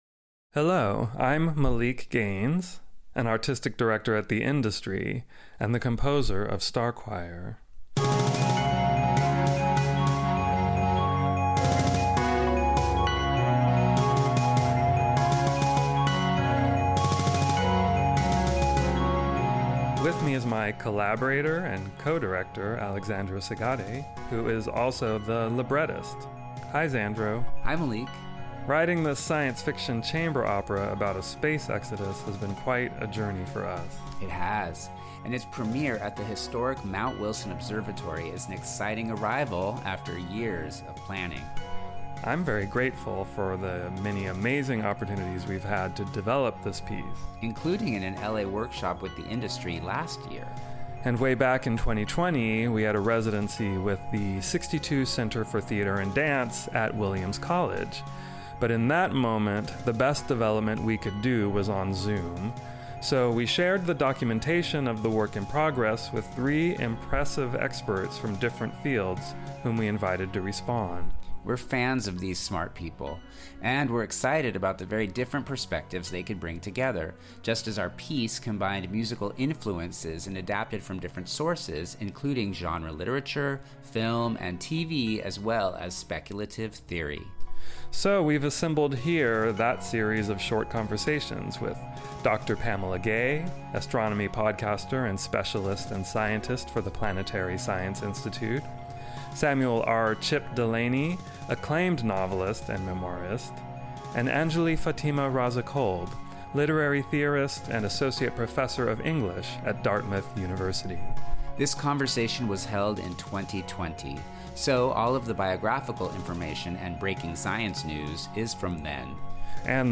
The-Industry-Presents-STAR-CHOIR-in-conversation.mp3